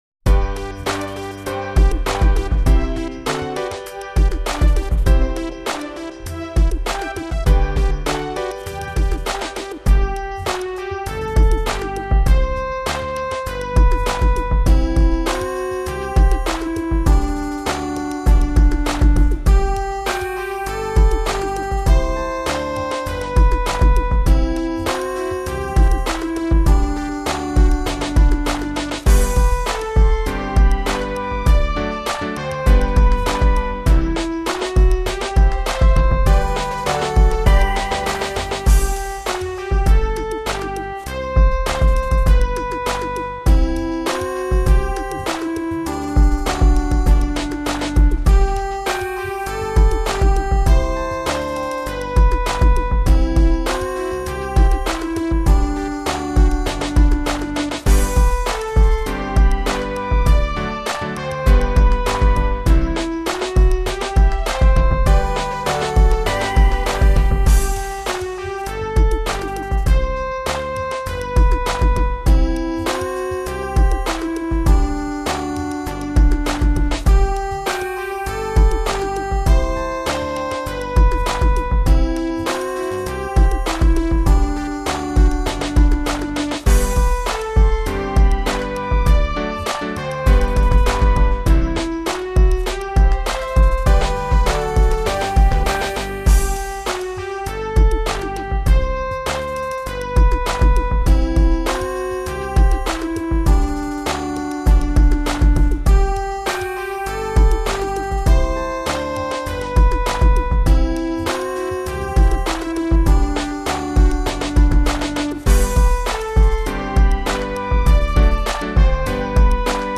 This stately Christmas hymn is by William Chatterton Dix and the tune by Conrad Kocher is called DIX, presumably in deference to the great man who wrote the words.
I did some of these backings some months ago and I’m not sure what possessed me here: